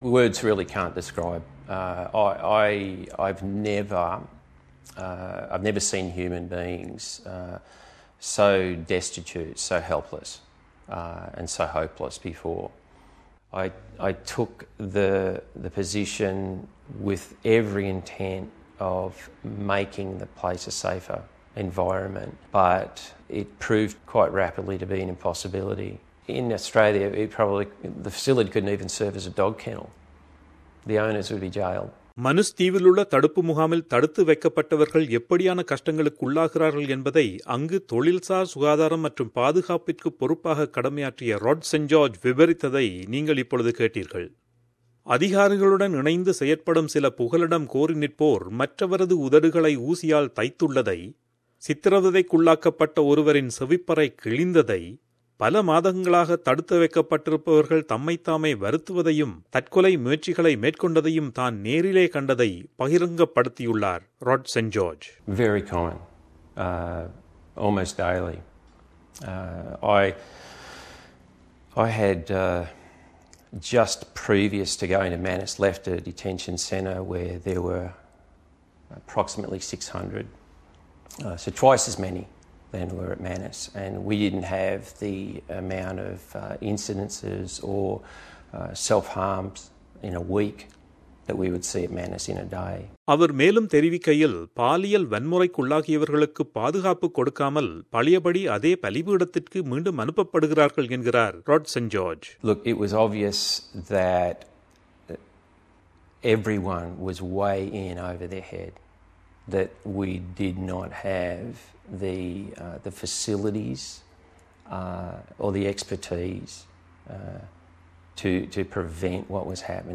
செய்தி விவரணத்தைத் தமிழில் தருகிறார்